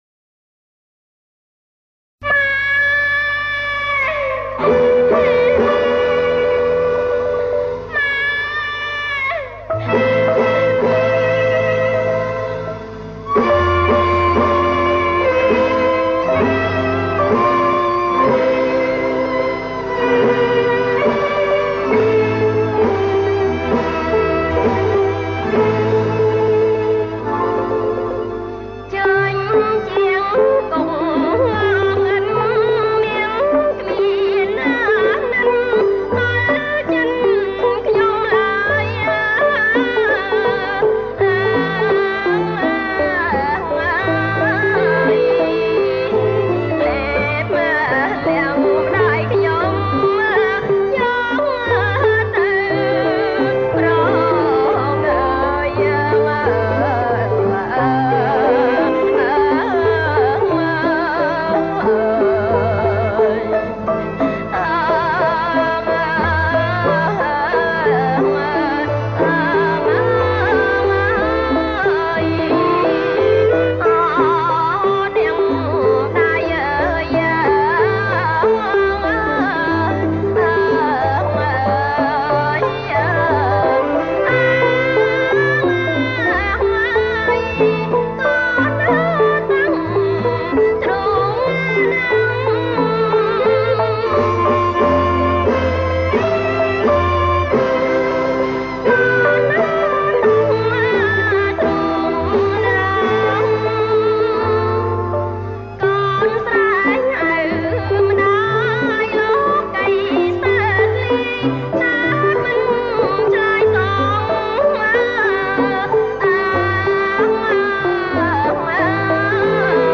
• ប្រគំជាចង្វាក់ រាំក្បាច់